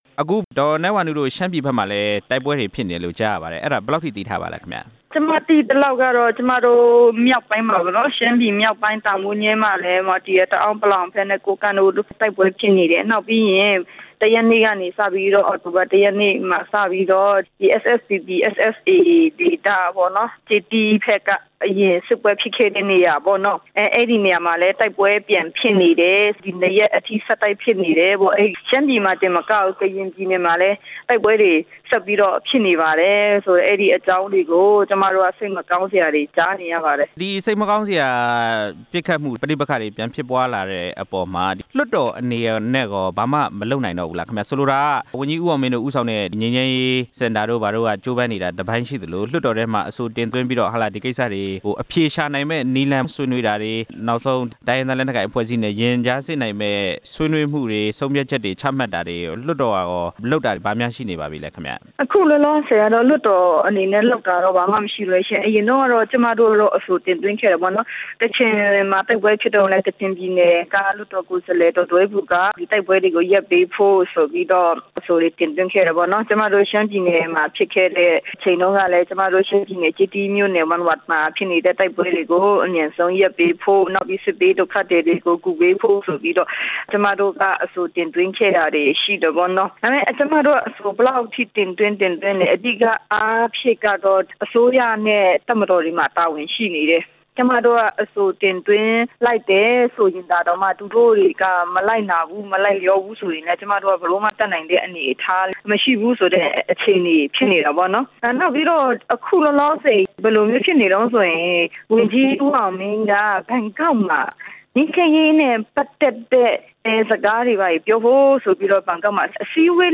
လွှတ်တော် ကိုယ်စားလှယ် ဒေါ်နန်းဝါနုနဲ့ မေးမြန်းချက်